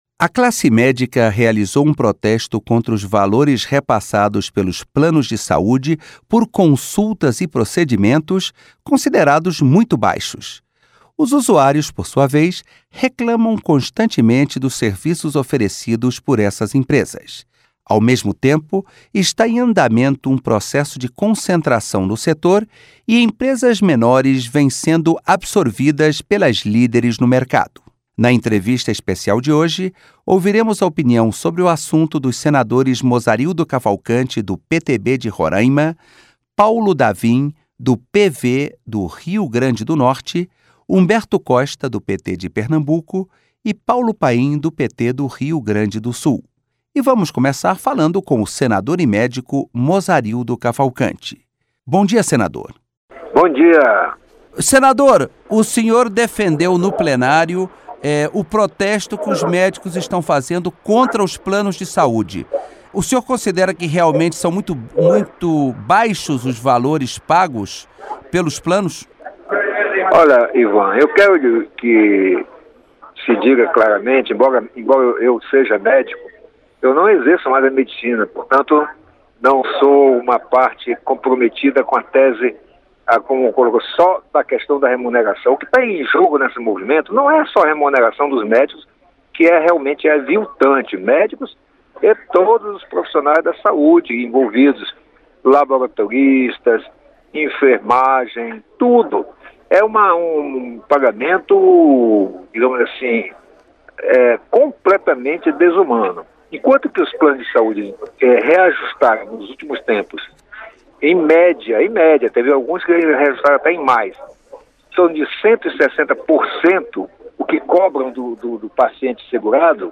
Entrevistas com os senadores Mozarildo Cavalcanti (PTB-RR) e Humberto Costa (PT-PE).